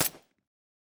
sounds / weapons / _bolt / 762_2.ogg